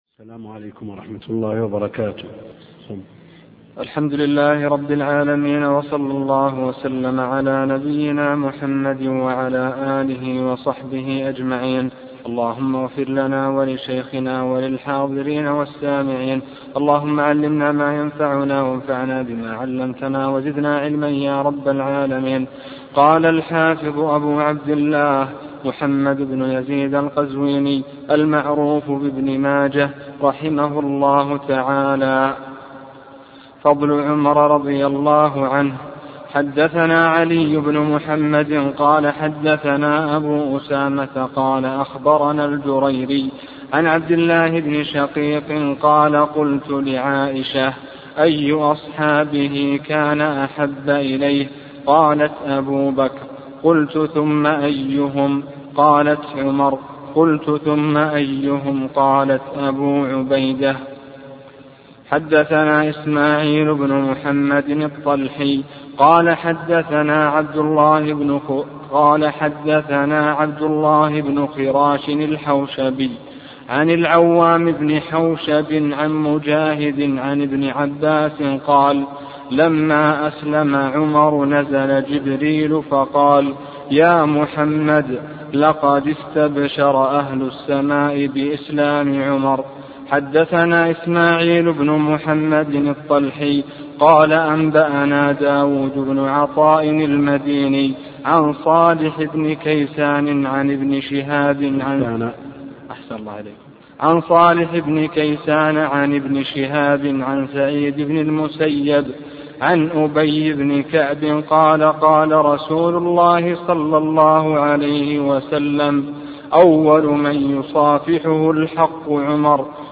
عنوان المادة الدرس (12) شرح سنن ابن ماجه تاريخ التحميل الأثنين 27 فبراير 2023 مـ حجم المادة 33.76 ميجا بايت عدد الزيارات 193 زيارة عدد مرات الحفظ 120 مرة إستماع المادة حفظ المادة اضف تعليقك أرسل لصديق